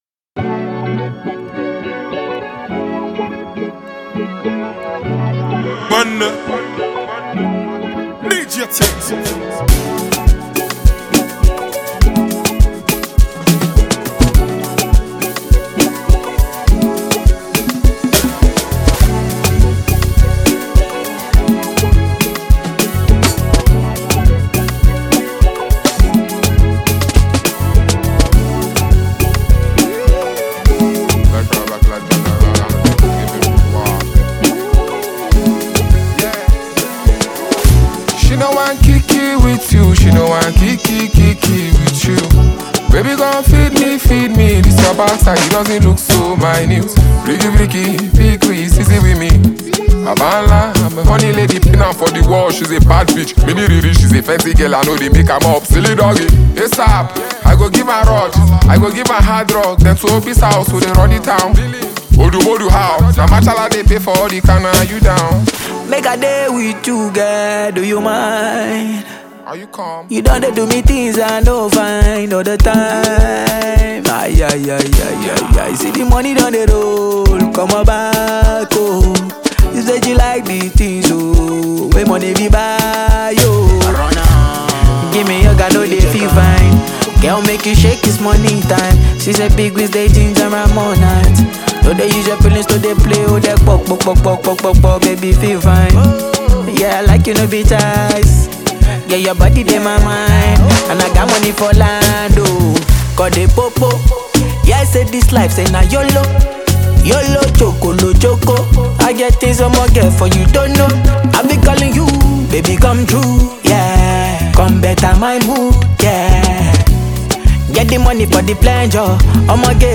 signature soulful vocals